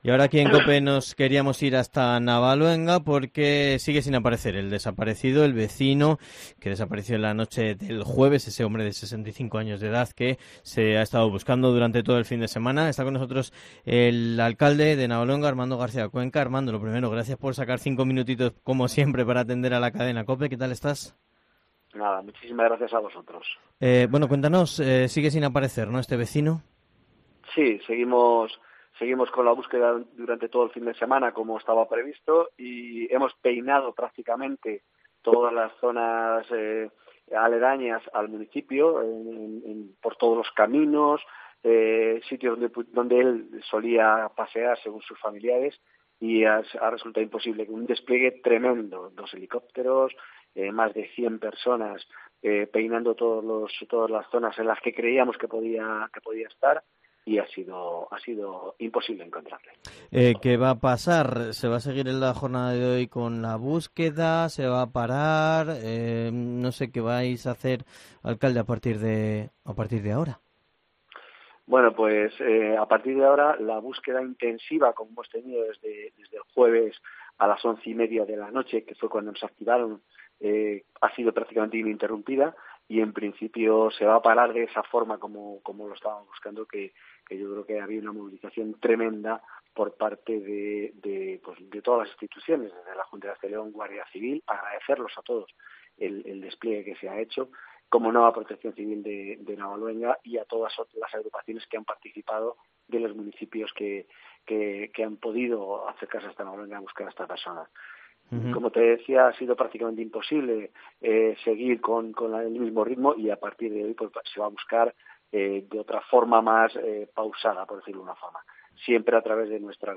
ENTREVISTA
Entrevista / El alcalde de Navaluenga, Armando García Cuenca, en COPE